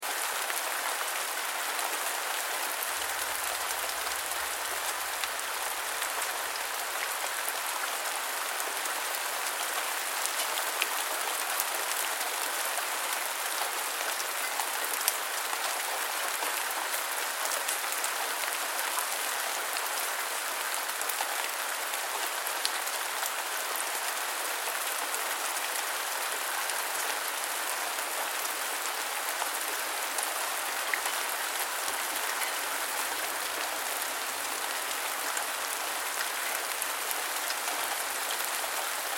دانلود صدای باران 11 از ساعد نیوز با لینک مستقیم و کیفیت بالا
جلوه های صوتی